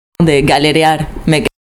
uitspraak Galerear
galerear_prononciation01.mp3